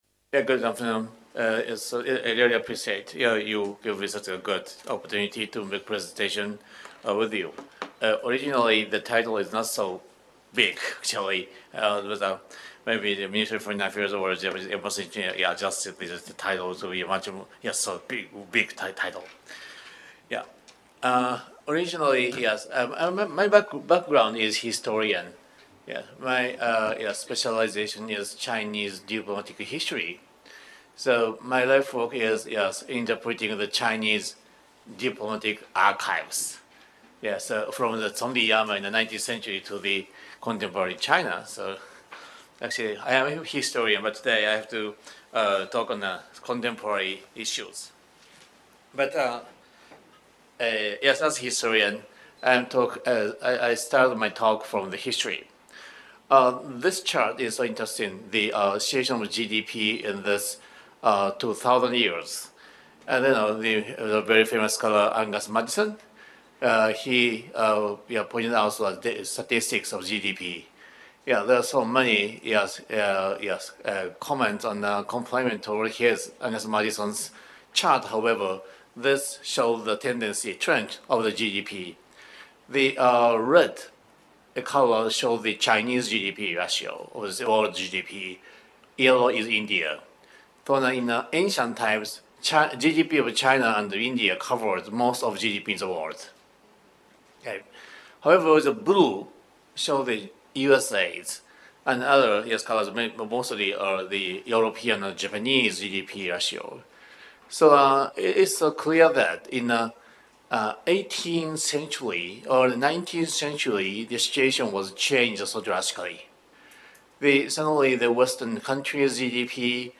Venue Conference Room, Level 2, ISIS Malaysia